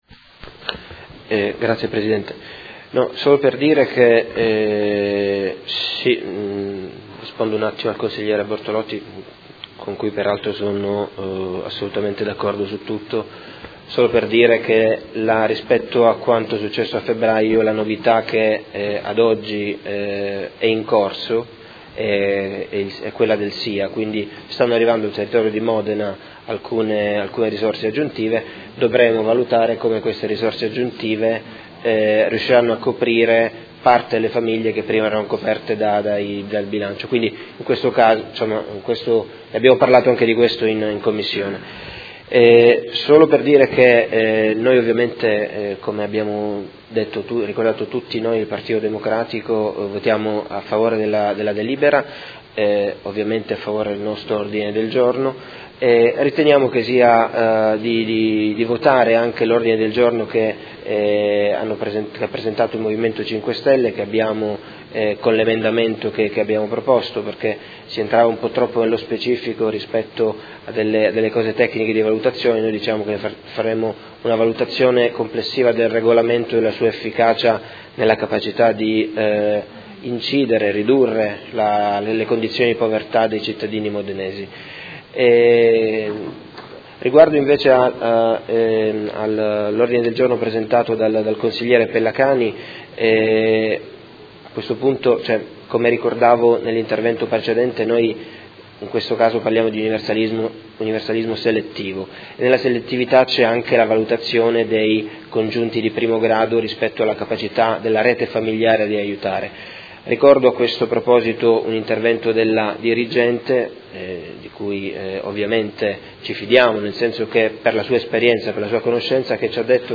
Tommaso Fasano — Sito Audio Consiglio Comunale
Seduta del 20/10/2016. Dichiarazione di voto su proposta di deliberazione e ordini del giorno sul tema della solidarietà civica